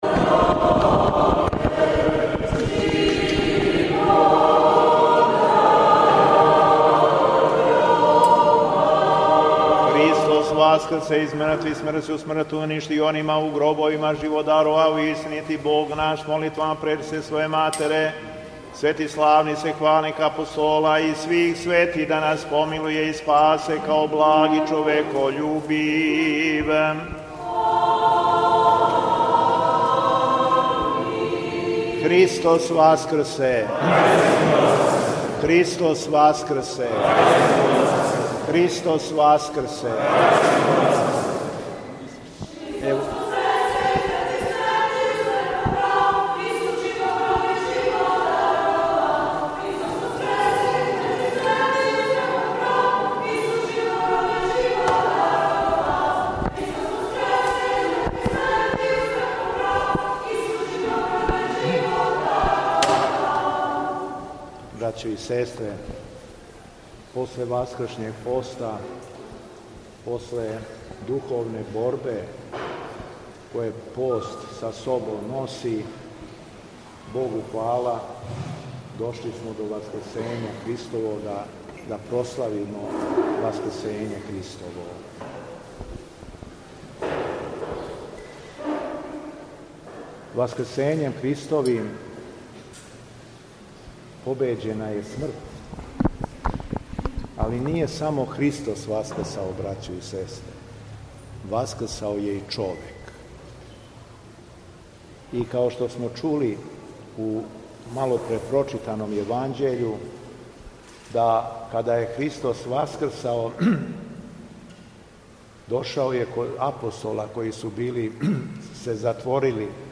Беседа Његовог Високопреосвештенства Митрополита шумадијског г. Јована
Митрополит Јован је служио Пасхално вечерње у цркви Светих апостола Петра и Павла уз учешће великог броја верника међу којима је било много најмлађих.